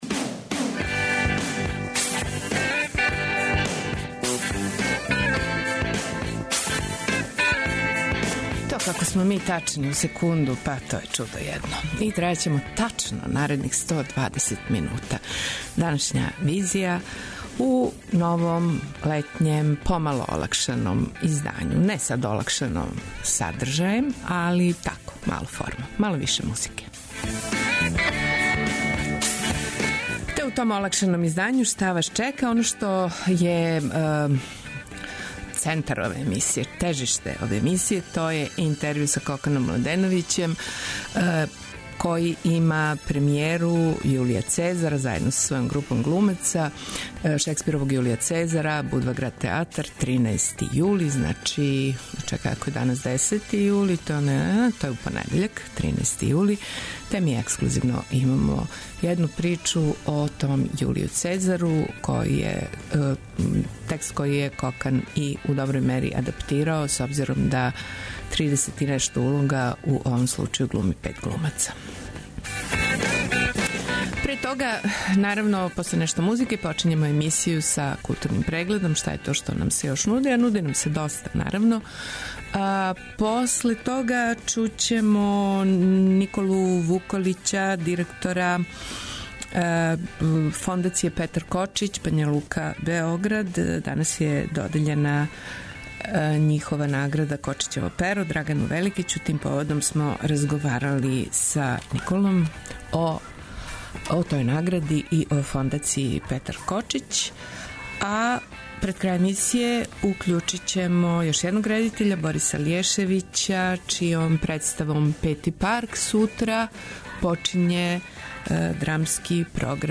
Социо-културолошки магазин, који прати савремене друштвене феномене.